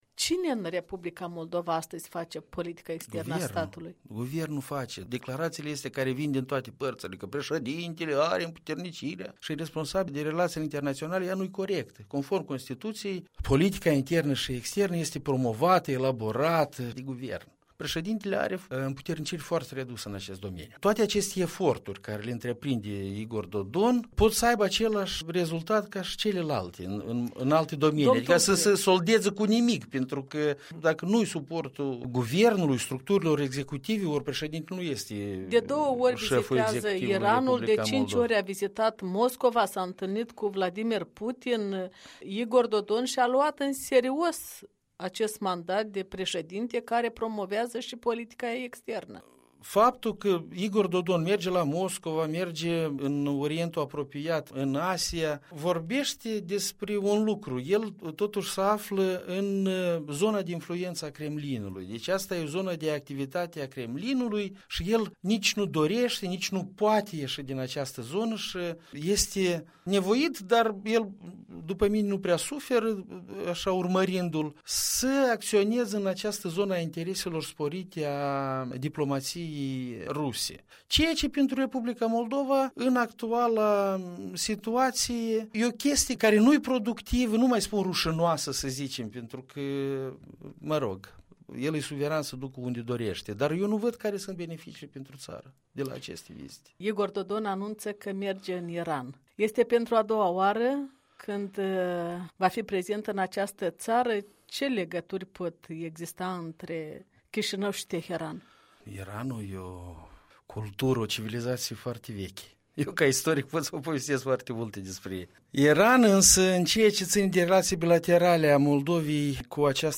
Alexei Tulbure în studioul Europei Libere la Chișinău
Un interviu cu diplomatul Alexei Tulbure